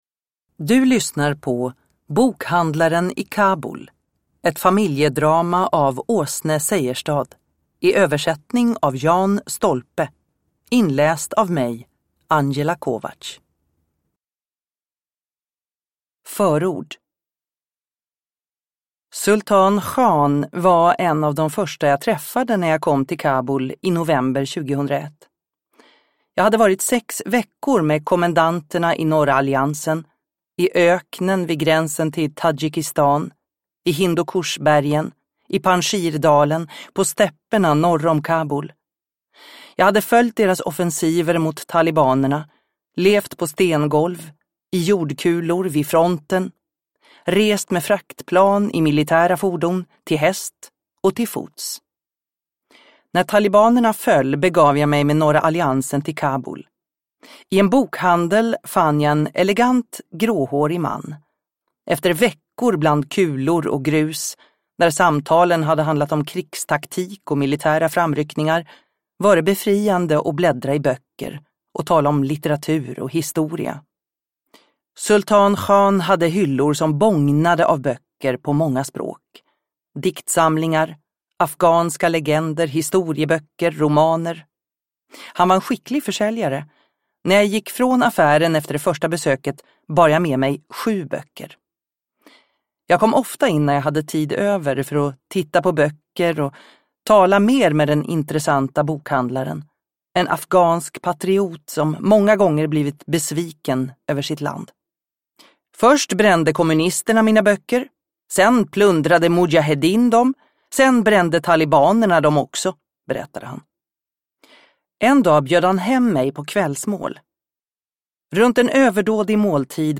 Bokhandlaren i Kabul – Ljudbok – Laddas ner